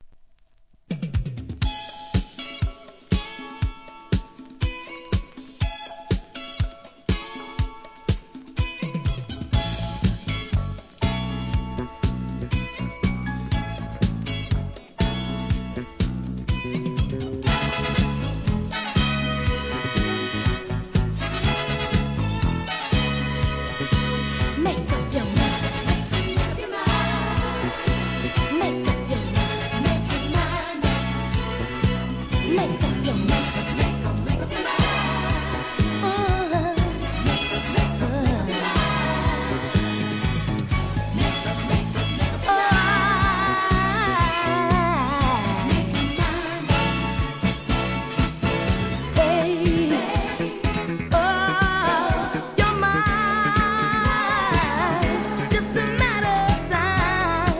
¥ 660 税込 関連カテゴリ SOUL/FUNK/etc...